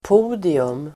Ladda ner uttalet
Uttal: [p'o:dium]